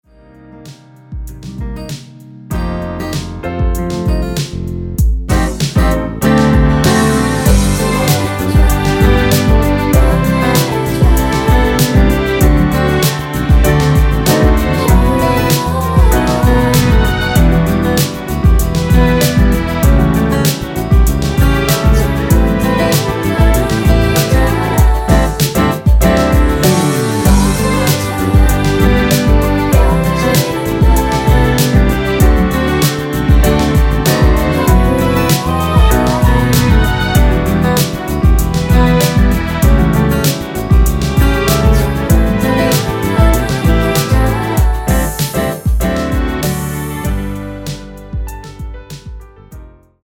원키 코러스 포함된 MR입니다.
Bb
앞부분30초, 뒷부분30초씩 편집해서 올려 드리고 있습니다.